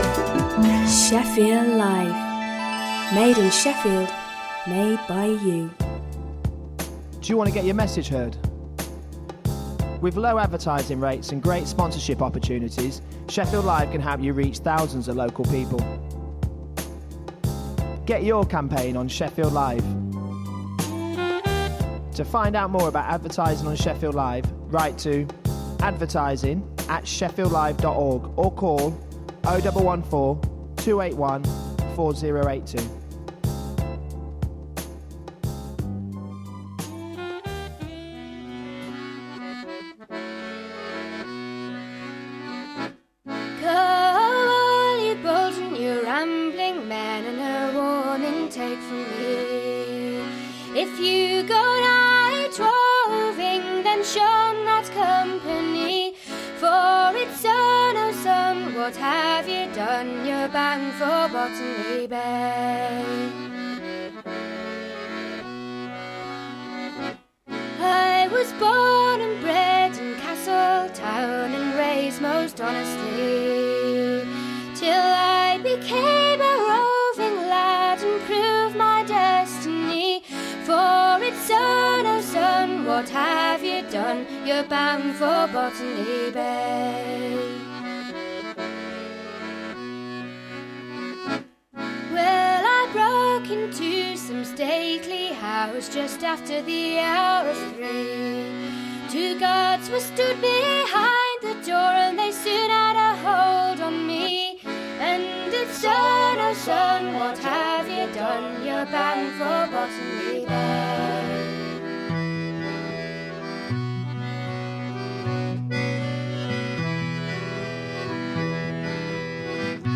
Come and join us to hear brilliant music that is not often played on other radio stations today.